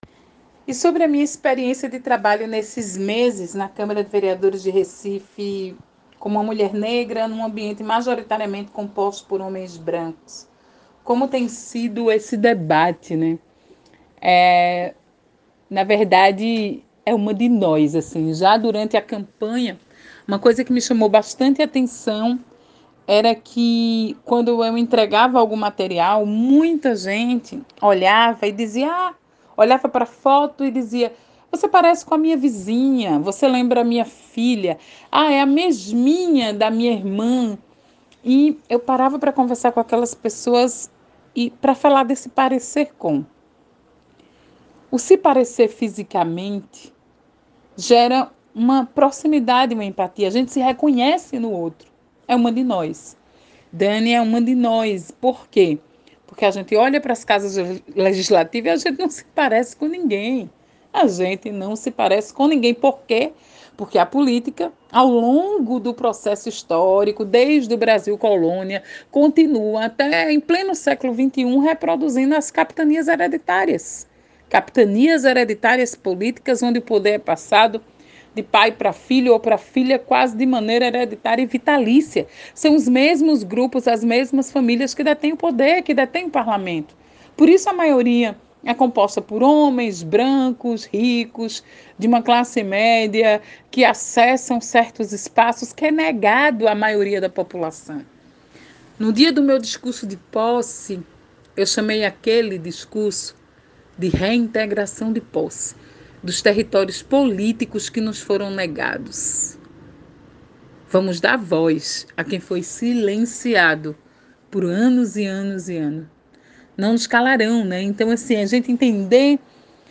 Ouça o relato da experiência de Dani Portela como vereadora do Recife:
Relato-Dani-Portela.ogg